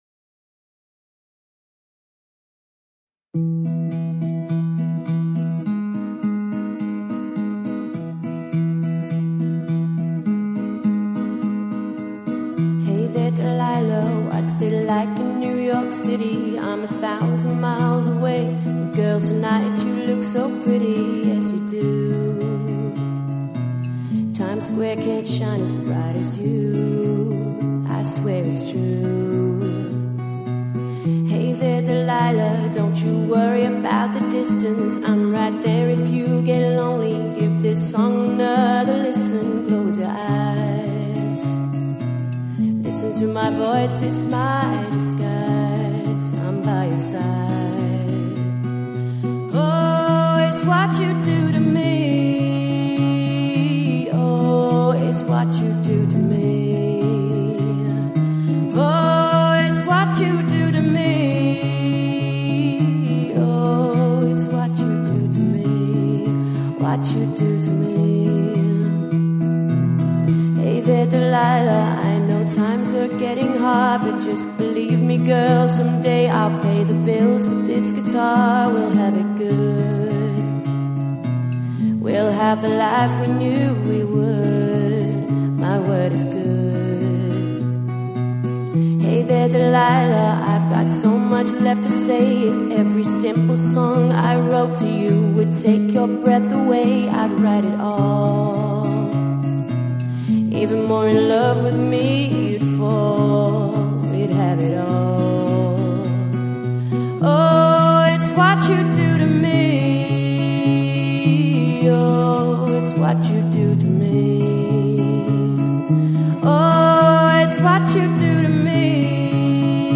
• Pop, rock and & party band to suit all ages